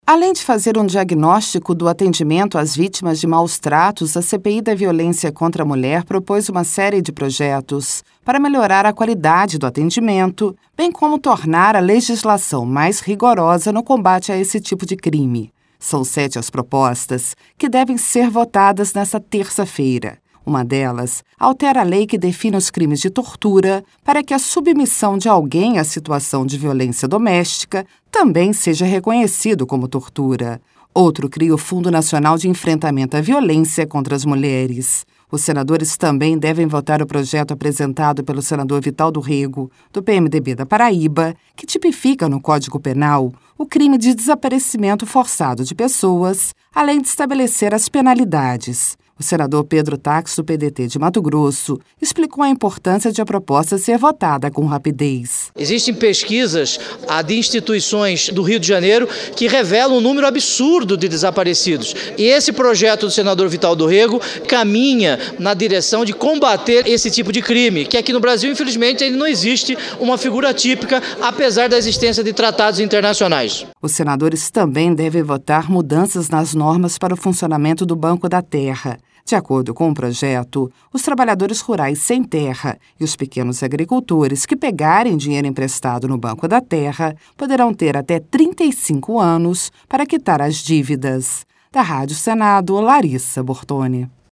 O senador Pedro Taques, do PDT de Mato Grosso, explicou a importância de a proposta ser votada com rapidez.